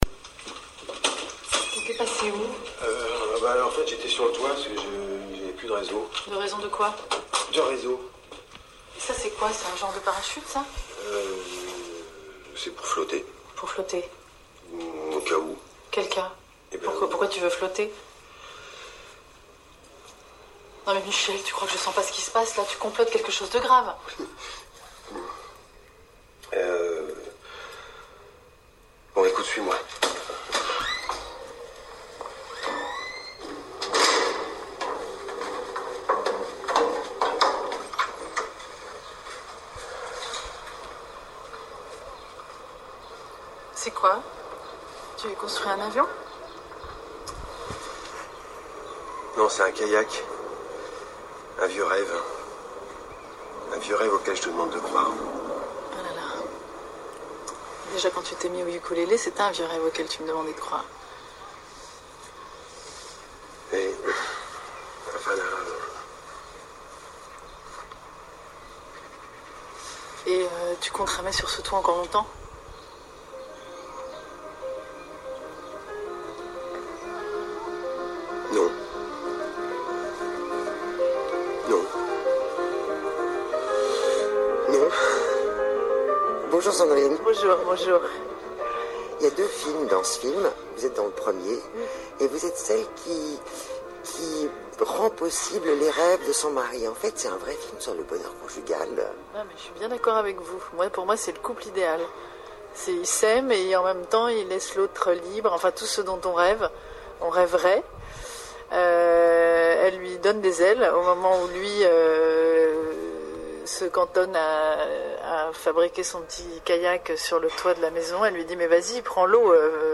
Voici un petit extrait d’une émission avec Sandrine Kiberlain. Cela commence par un passage du film, qui donne parfaitement le ton et où le « non » de la fin répété trois fois par Bruno Podalydes dit tout. Je me régale à écouter comment on peut jouer avec un simple mot !